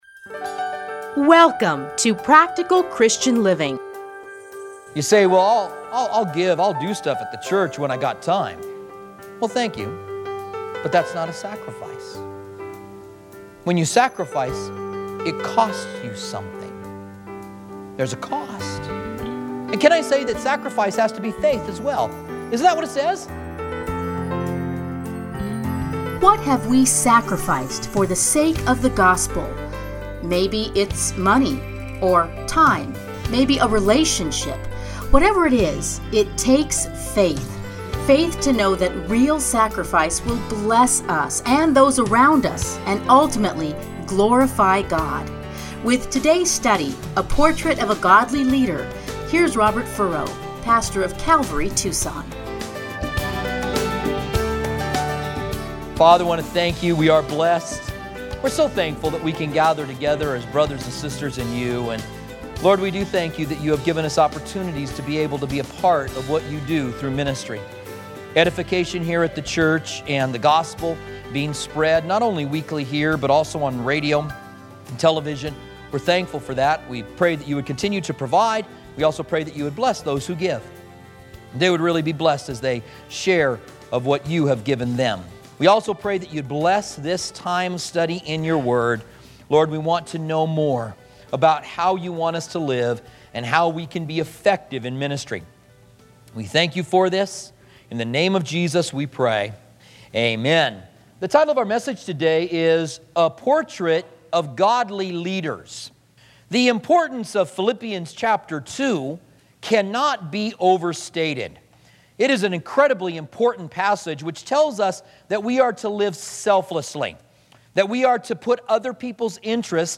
Listen here to his commentary on Philippians.